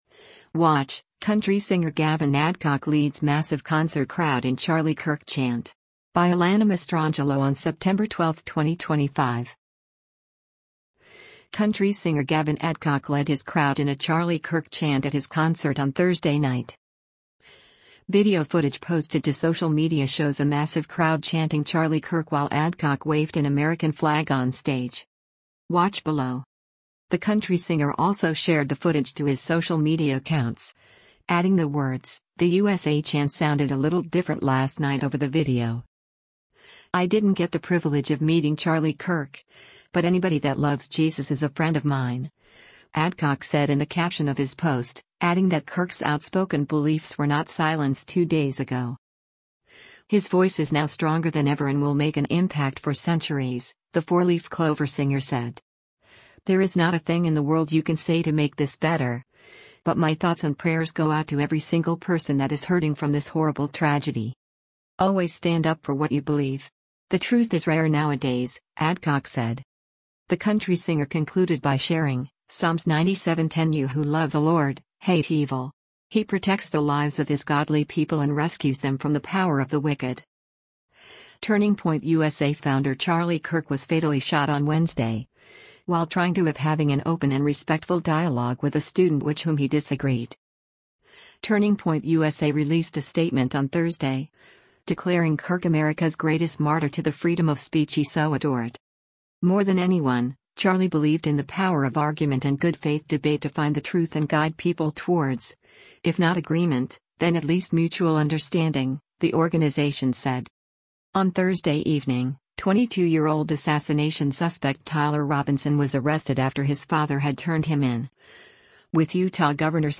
Country singer Gavin Adcock led his crowd in a “Charlie Kirk” chant at his concert on Thursday night.
Video footage posted to social media shows a massive crowd chanting “Charlie Kirk” while Adcock waved an American flag onstage.
The country singer also shared the footage to his social media accounts, adding the words: “The USA chant sounded a little different last night” over the video.